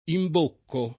imbocco [ imb 1 kko ], -chi